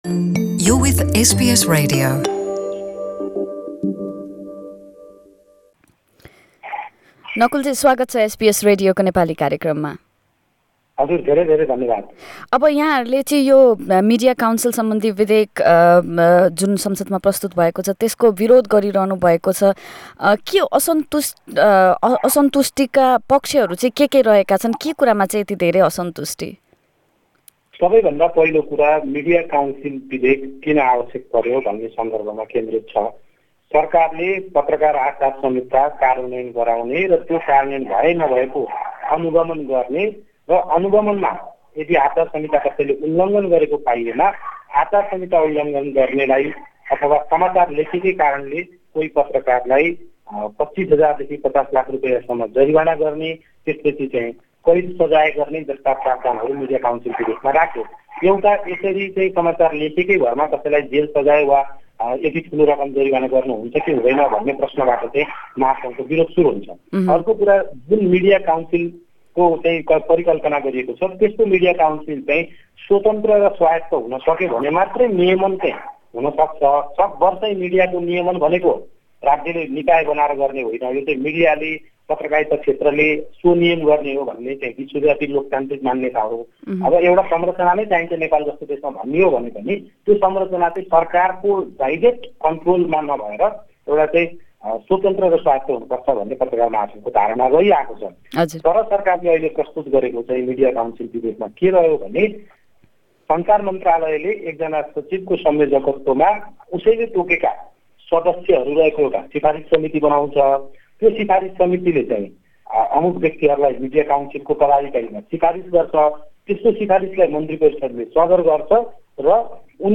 हाम्रो पूर्ण कुराकानी मिडिया प्लेयरमा प्ले बटन थिचेर सुन्नुहोला Share